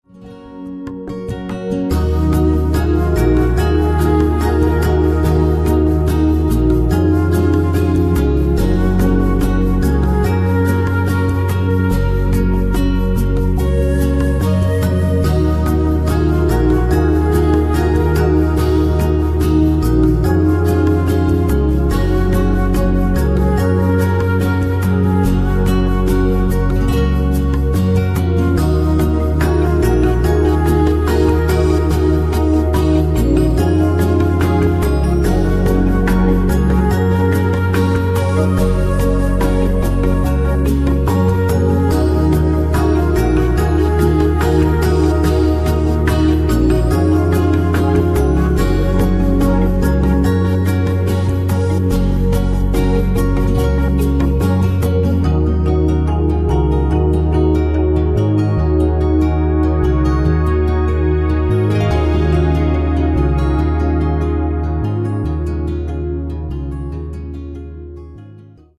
Afslappende Massage i en smuk og harmonisk composition